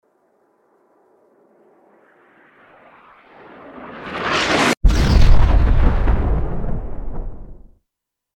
mortarimpactflame.mp3